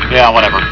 Voice actor Jeff Bennett is the voice of Johny Bravo, plus many others in the cartoon.